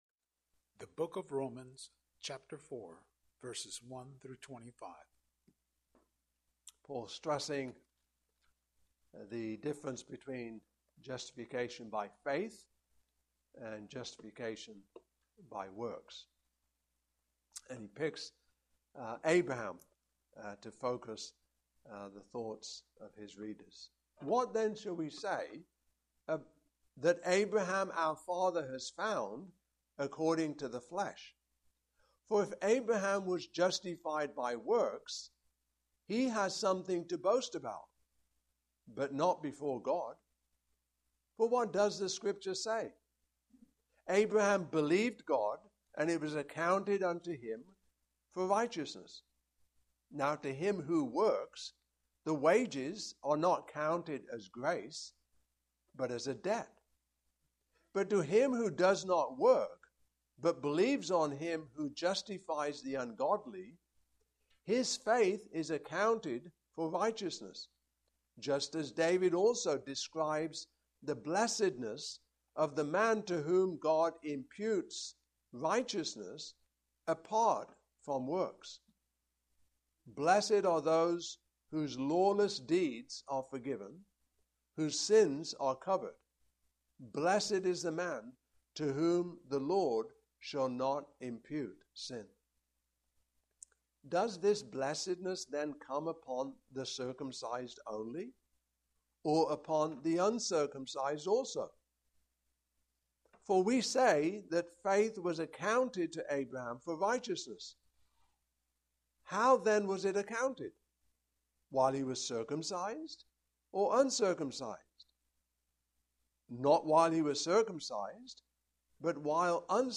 Romans 4:1-25 Service Type: Morning Service « Holy Children Lord’s Supper